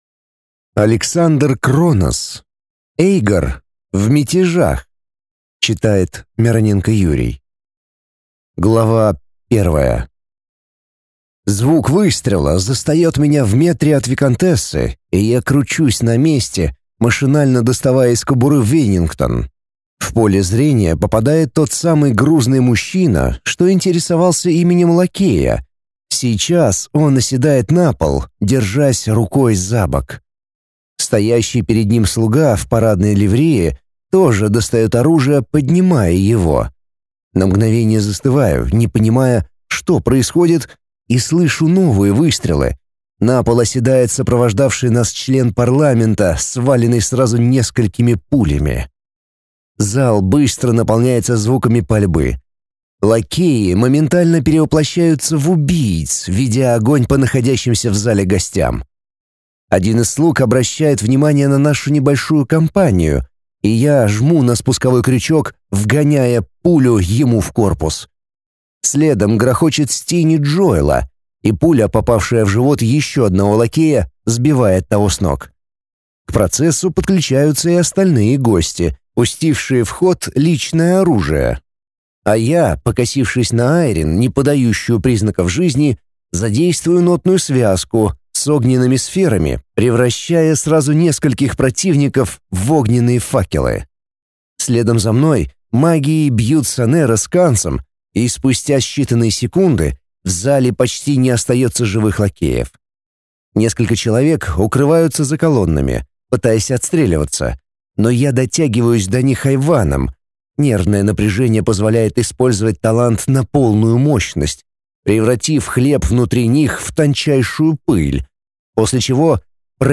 Аудиокнига Эйгор. В мятежах | Библиотека аудиокниг